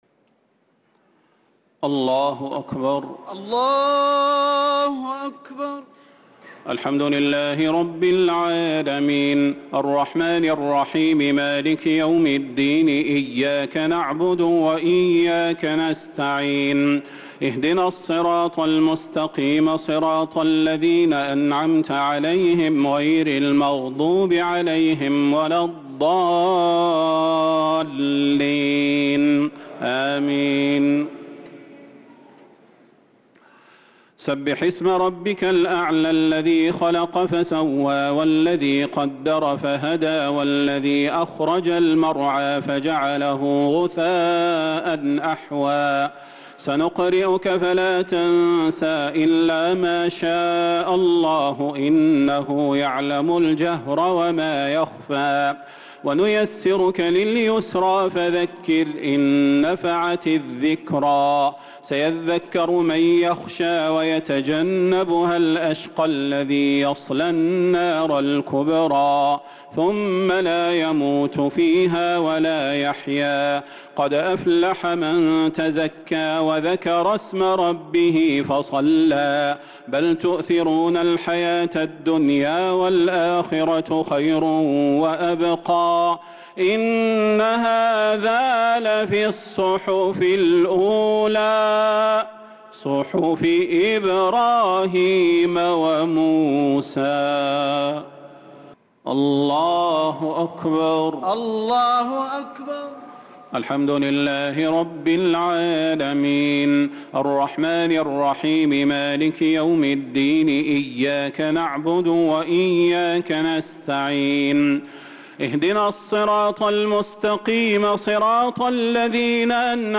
صلاة الشفع و الوتر ليلة 25 رمضان 1446هـ | Witr 25th night Ramadan 1446H > تراويح الحرم النبوي عام 1446 🕌 > التراويح - تلاوات الحرمين